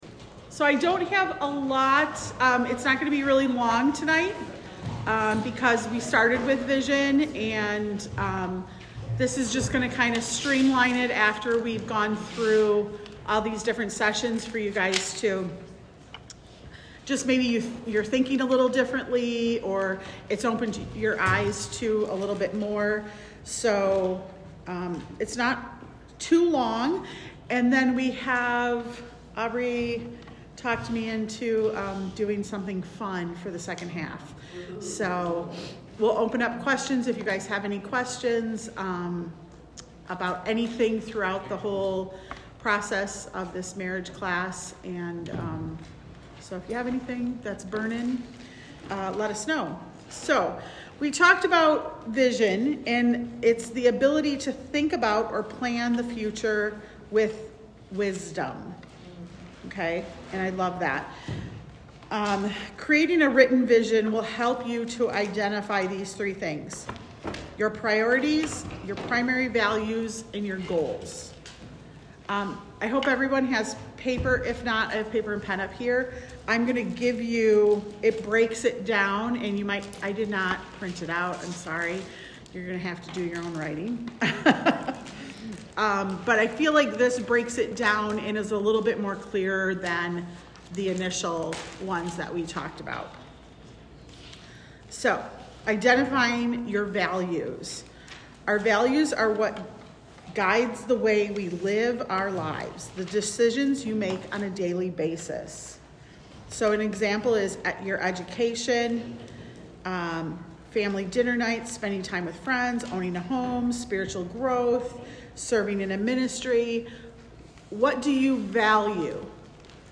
God's Perfect Plan Service Type: Class Week 11 of God’s Perfect Plan